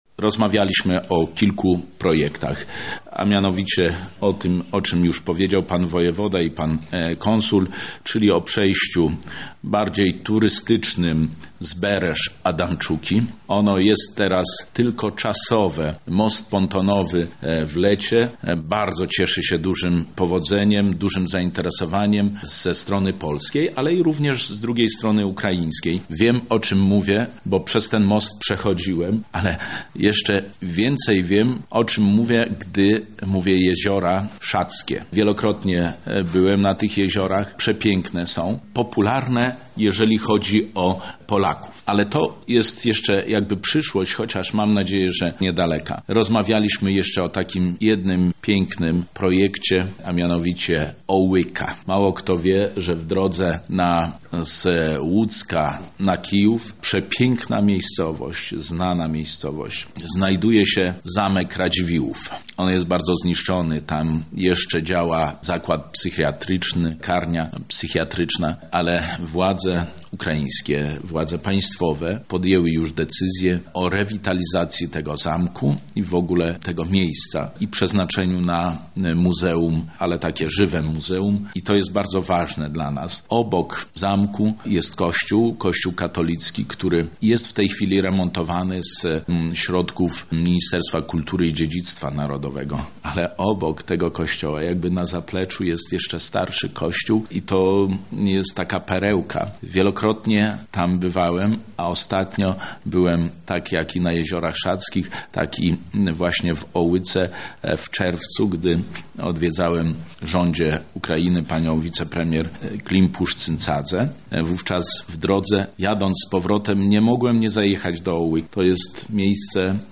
W wydarzeniu wziął udział też Wicemarszałek Województwa Lubelskiego, Zbigniew Wojciechowski.